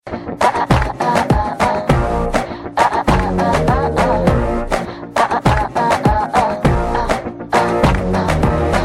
Female Voice SMS Klingelton Kostenlos
Kategorien SMS Töne